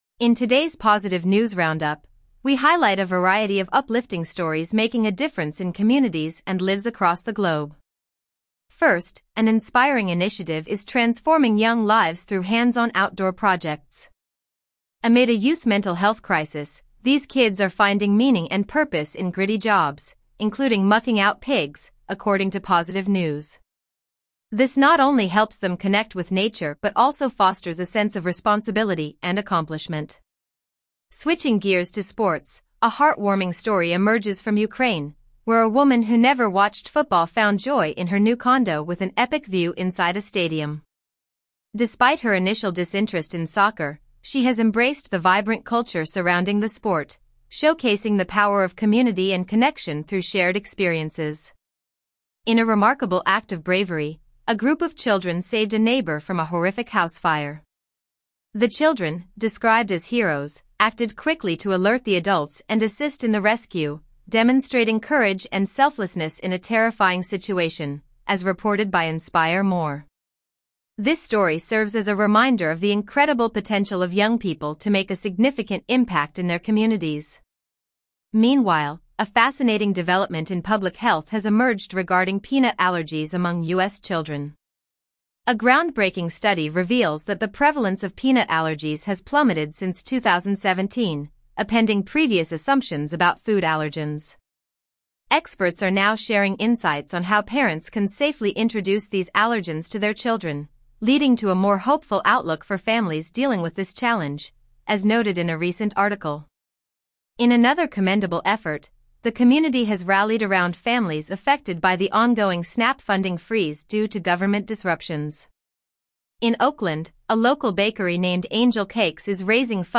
Good News Summary - Daily Audio News Transcript